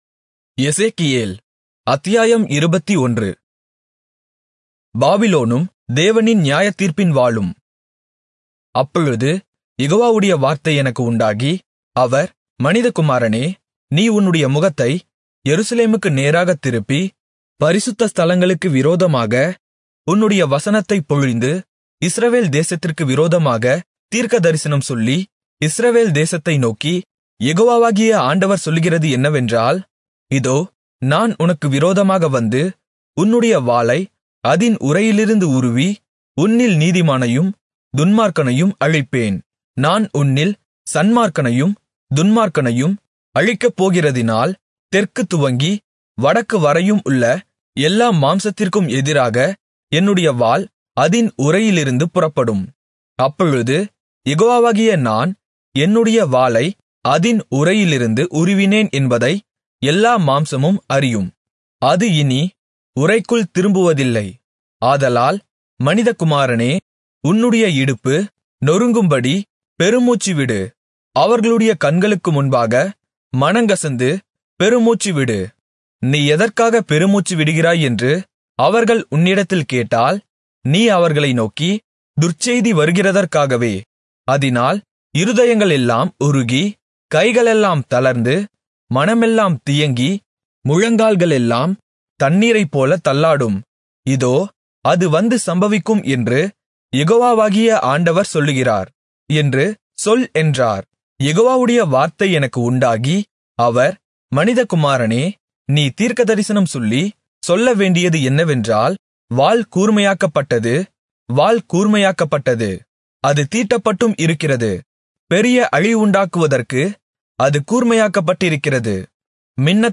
Tamil Audio Bible - Ezekiel 43 in Irvta bible version